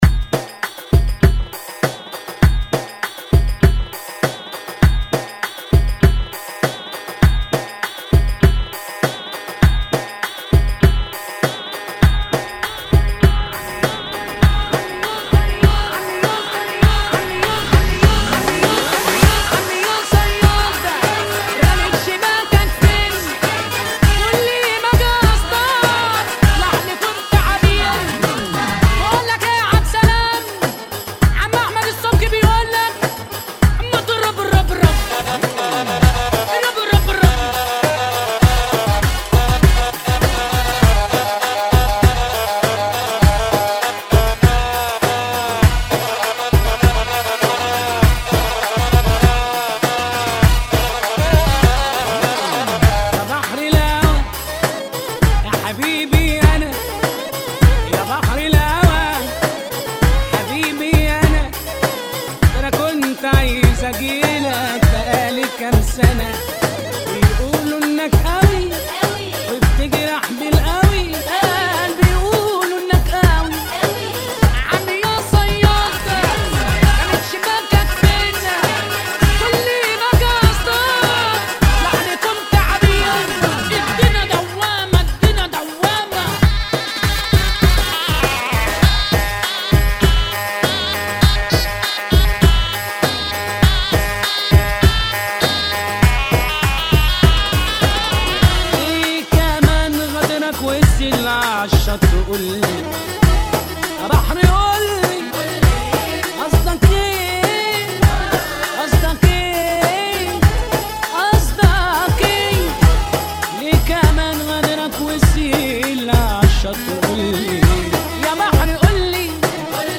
[ 100 bpm ]